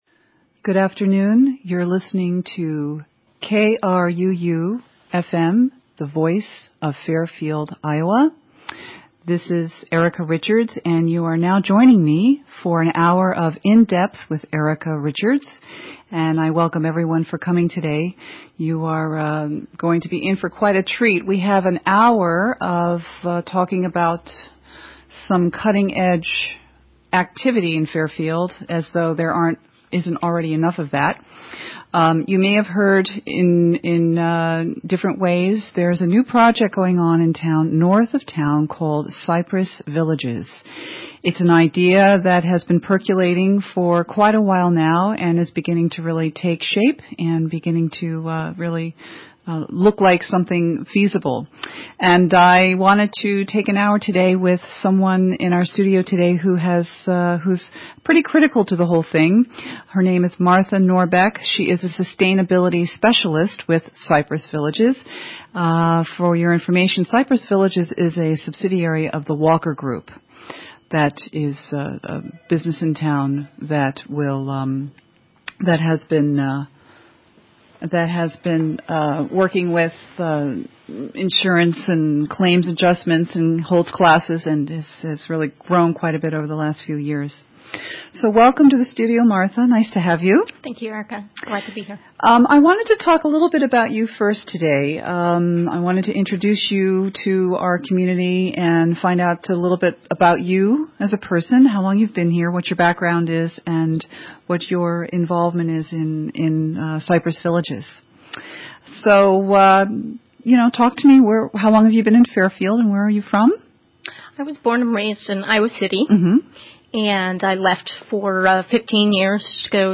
KRUU_interview_cypress.mp3